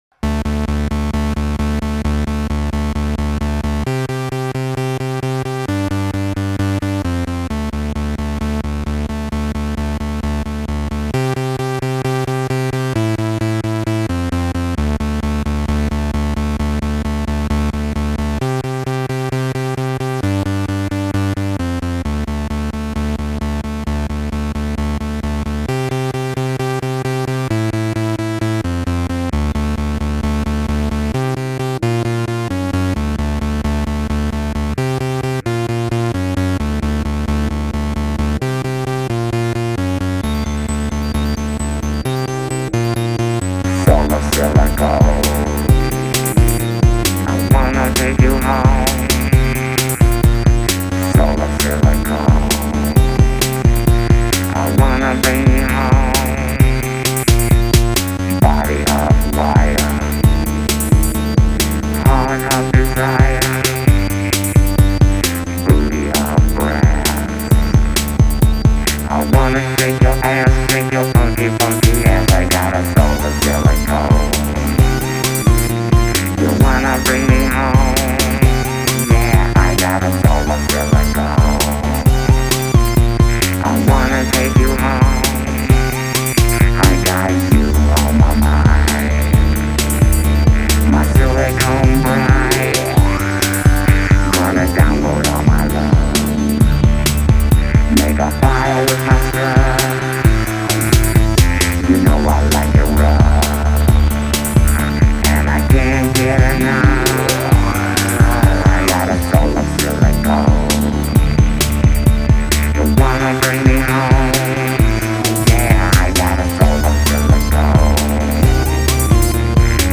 Electro Détroit, mon péché mignon.